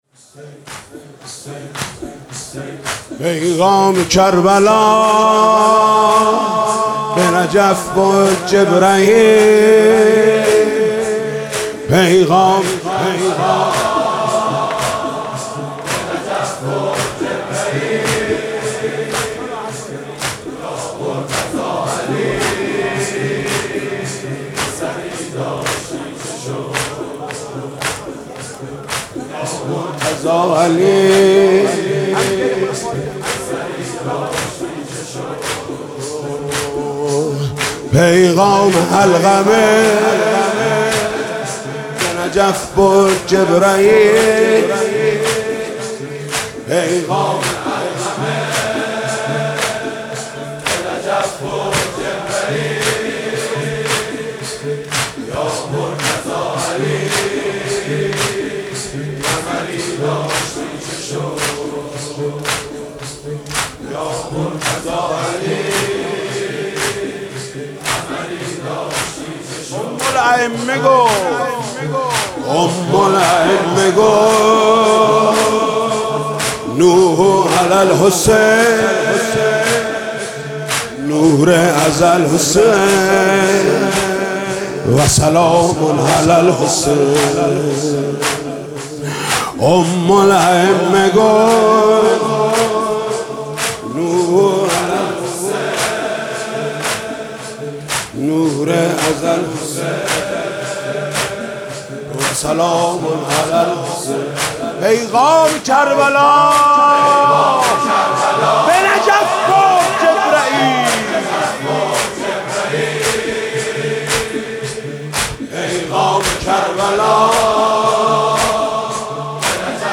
دانلود مداحی پيغام كربلا به نجف برد جبرئیل/ محمود کریمی/ شب عاشورا محرم96